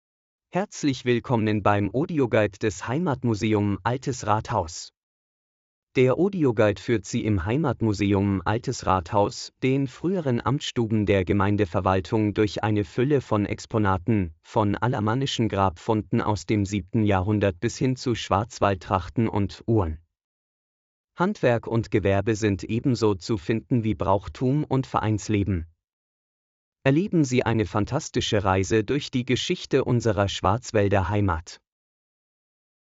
Audioguide des Heimatmuseum "Altes Rathaus" Loßburg